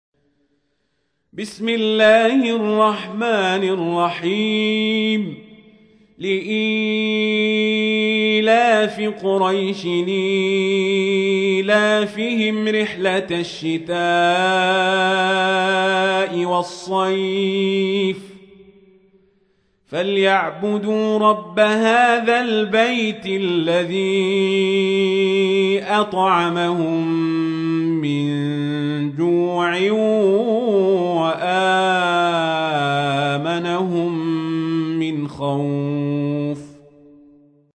تحميل : 106. سورة قريش / القارئ القزابري / القرآن الكريم / موقع يا حسين